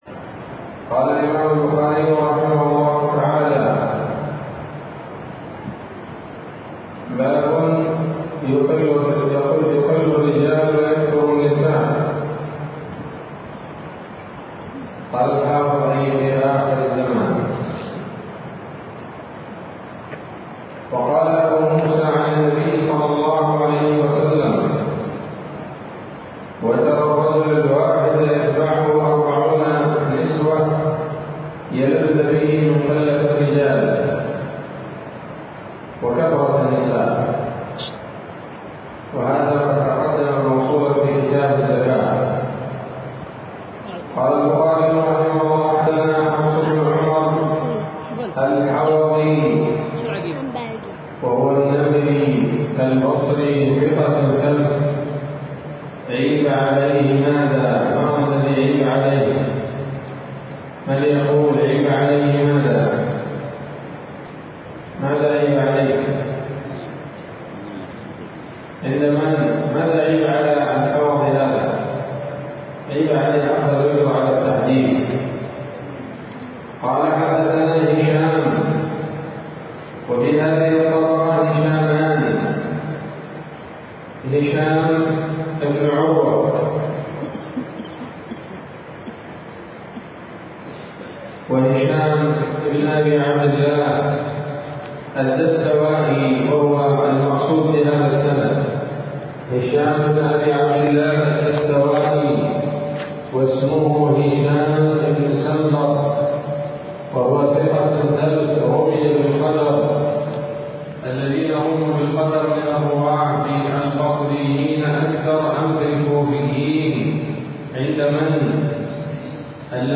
الدرس السابع والثمانون من كتاب النكاح من صحيح الإمام البخاري